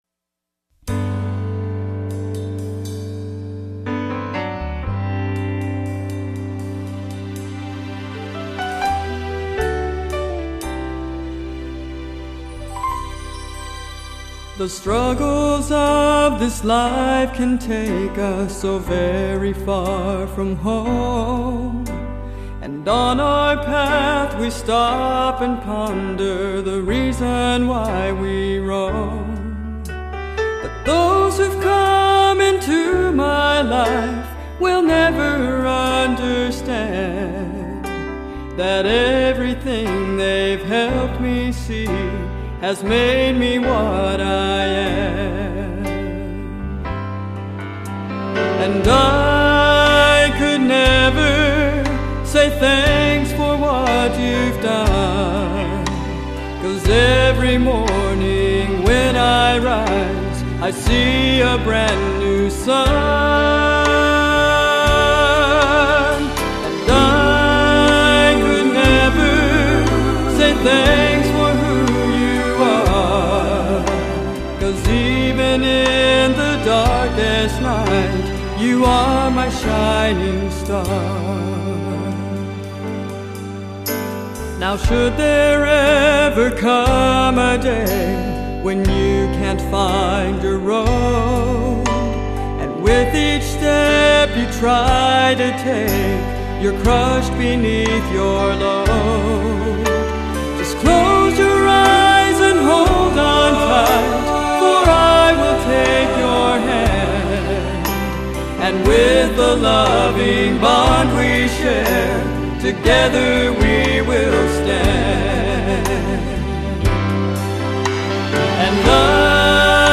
I was filled with overwhelming gratitude and love for them and this song is the way it came out. I walked in the door of my house, sat down at the piano, played and sang it.